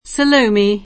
vai all'elenco alfabetico delle voci ingrandisci il carattere 100% rimpicciolisci il carattere stampa invia tramite posta elettronica codividi su Facebook Salomè [ S alom $+ ] (non Salomé ; antiq. Salome [ S al 0 me o S# lome ]) pers. f. bibl.